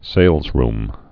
(sālzrm, -rm)